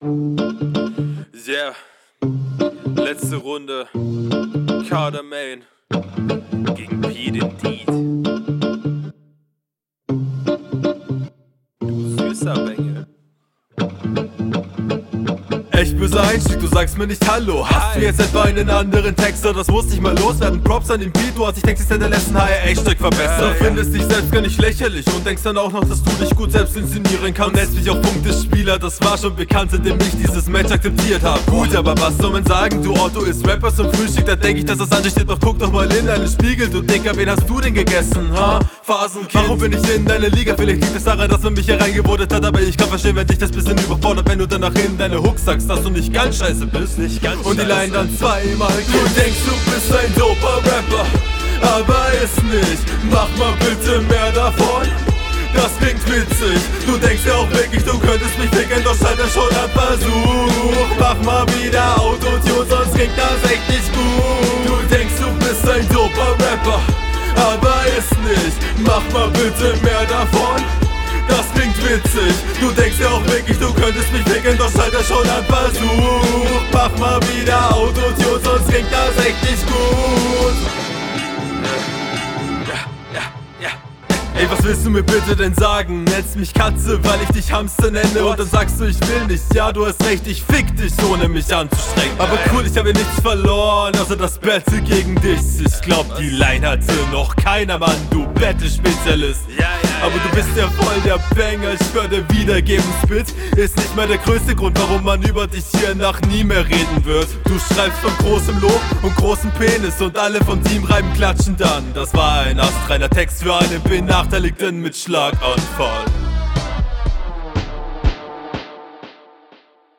Flowlich am stärksten von den Runden.